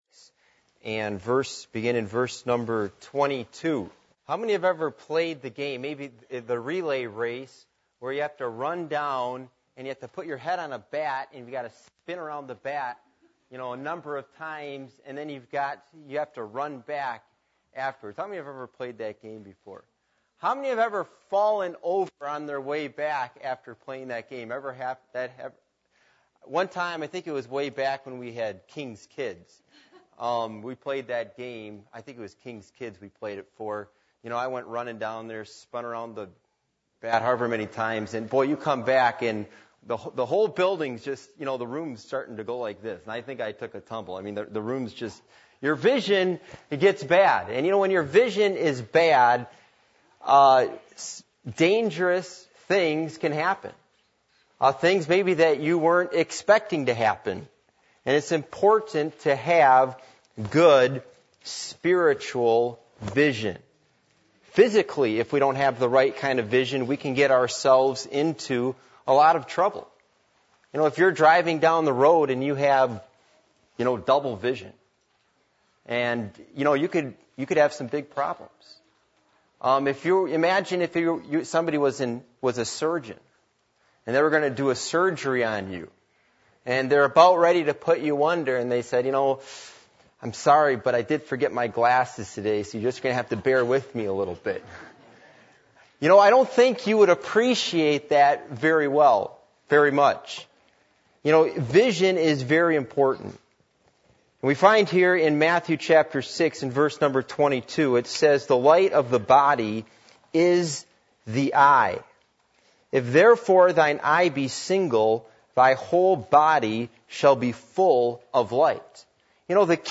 Passage: Matthew 6:22-24 Service Type: Midweek Meeting